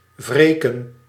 Ääntäminen
IPA: /vɑ̃.ʒe/